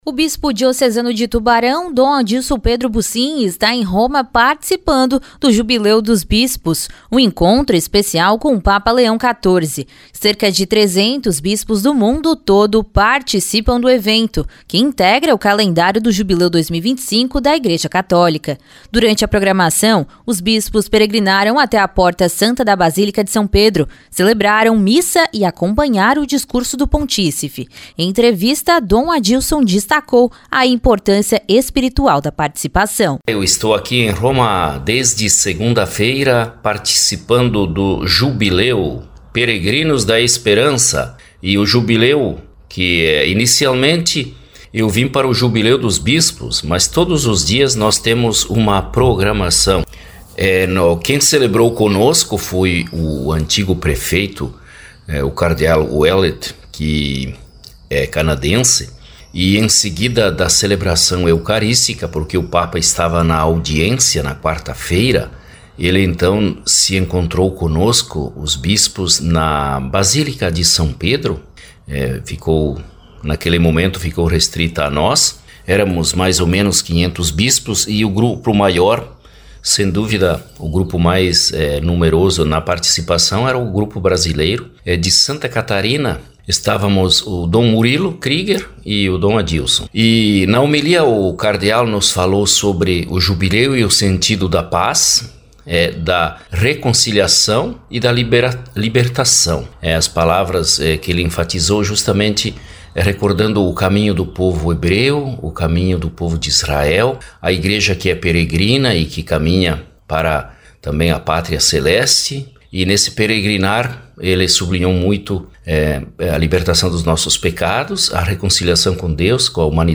Em entrevista, Dom Adilson destacou a importância espiritual da participação.